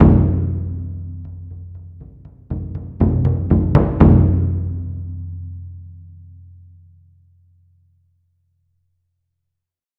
その響きを再現するために、TAIKO THUNDERはスタジオではなくホールでサンプリング収録を行いました。
• Front：太鼓の表側（打面側）の皮ダイレクトマイクのサウンド（モノラル）です。